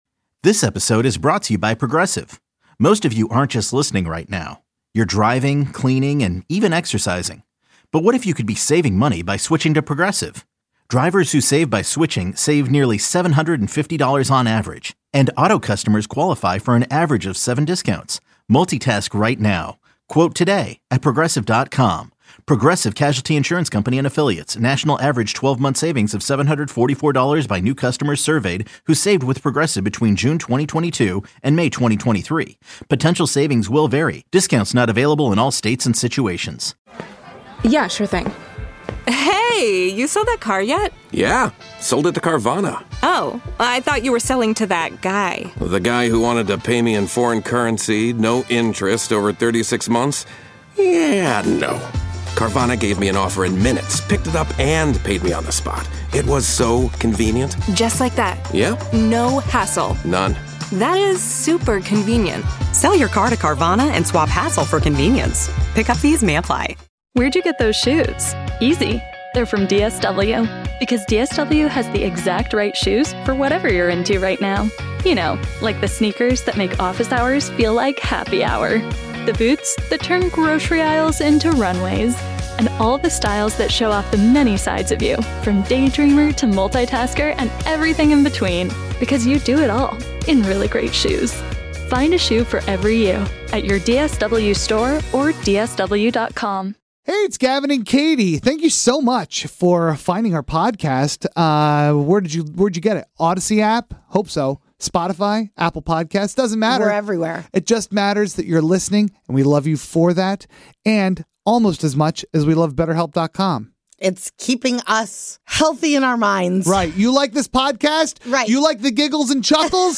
The Wake Up Call is a morning radio show based in Sacramento, California, and heard weekday mornings on 106.5 the End.